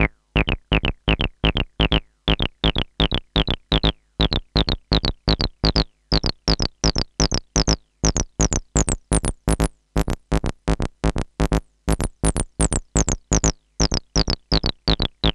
cch_acid_single_125_Am.wav